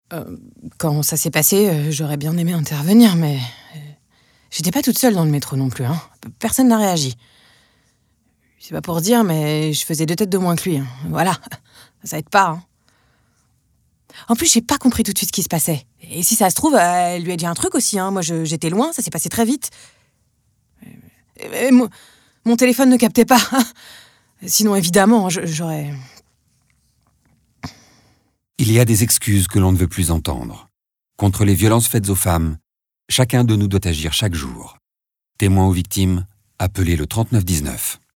Voix off
25 - 60 ans - Mezzo-soprano